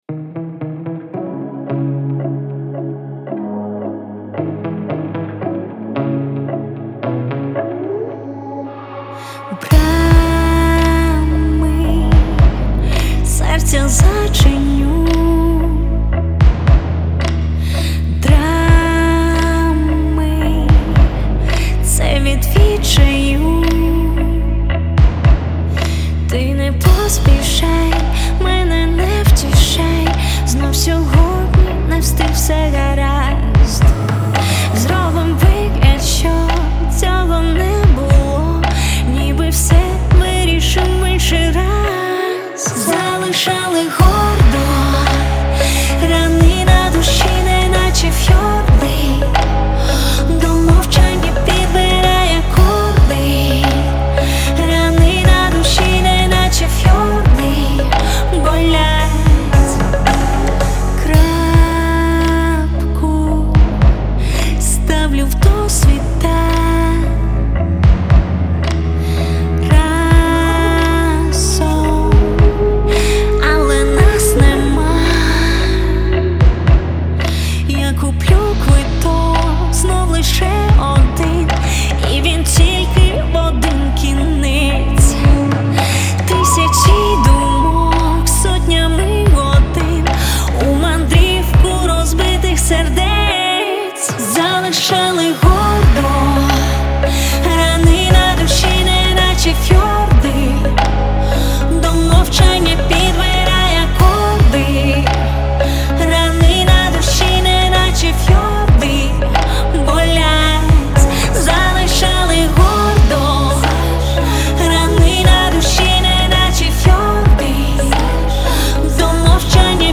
это мелодичный трек в жанре инди-поп
красивыми гармониями
нежным вокалом